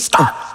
TS - CHANT (10).wav